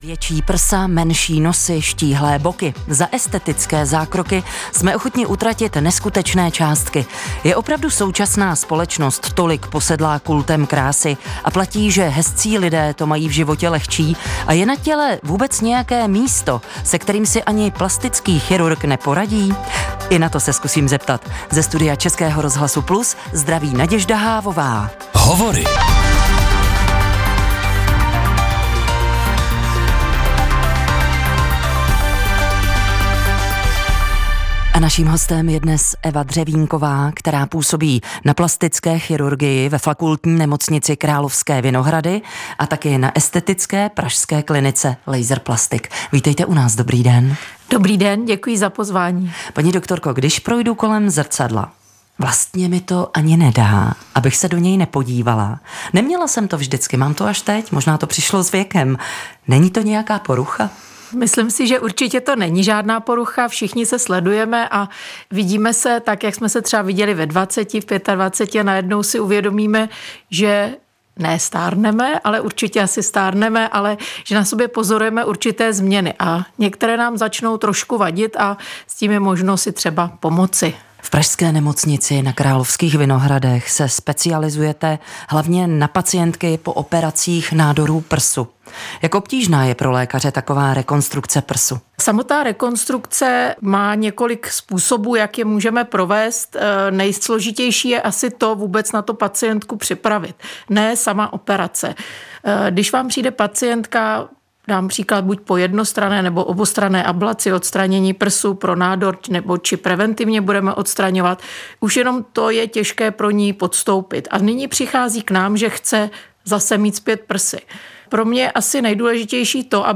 Téma plastických operací nám ve studiu Českého rozhlasu Plus přiblížil